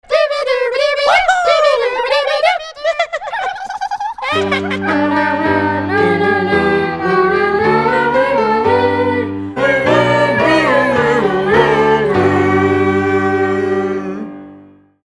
Музыкальная тема сериала,